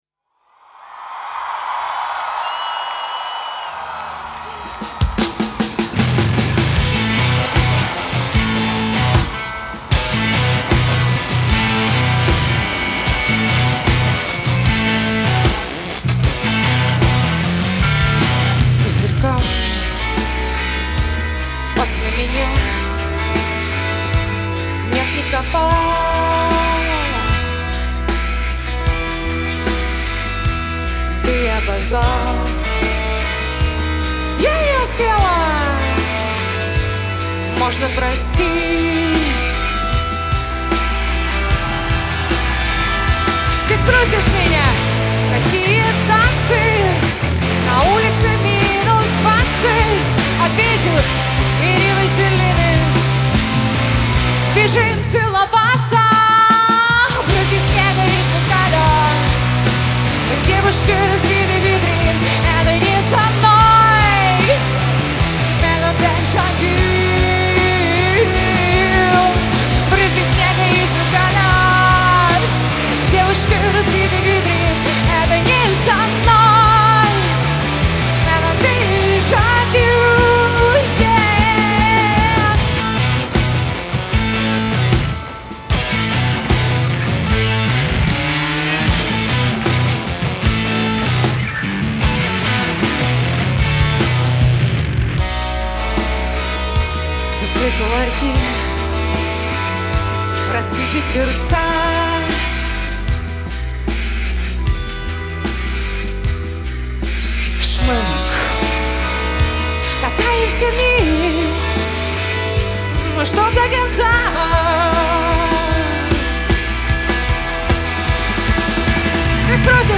12__bryzgi_Live.mp3